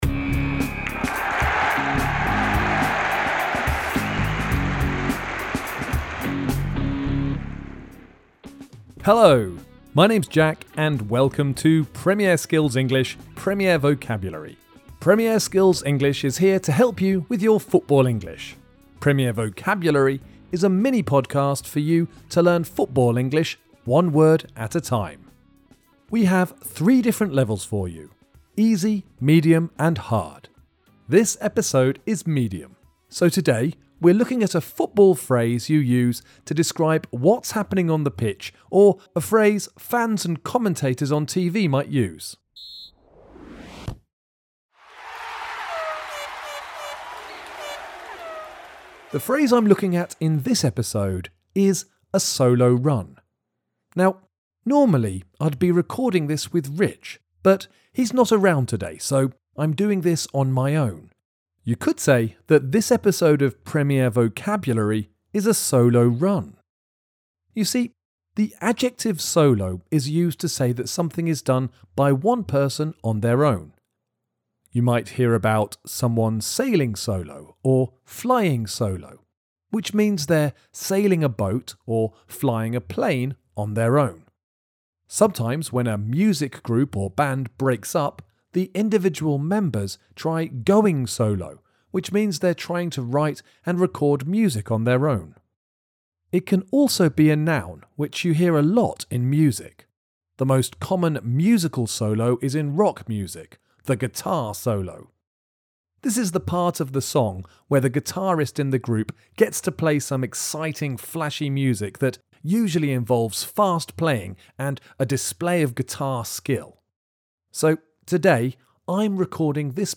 Premier Vocabulary is a mini-podcast for you to learn football English one word at a time.